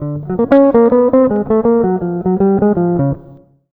160JAZZ  6.wav